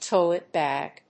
音節tóilet bàg